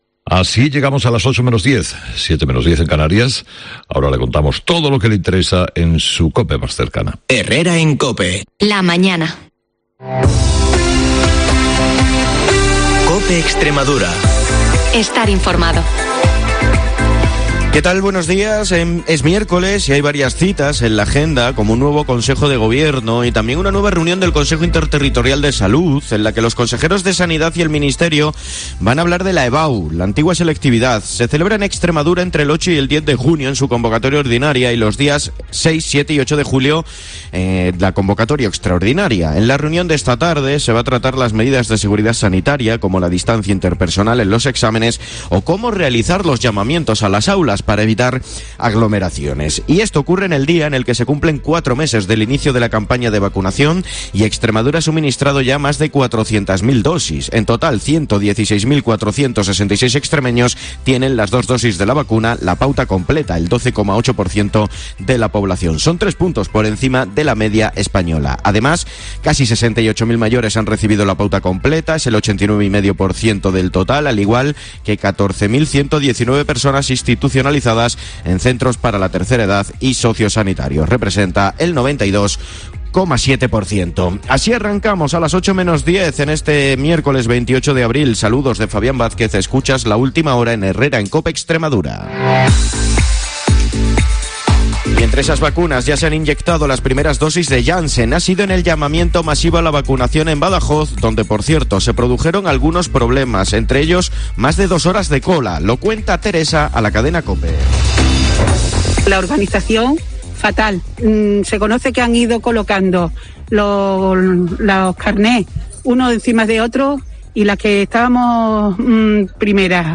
A las 7:20 y 7:50 horas el informativo líder de la radio en Extremadura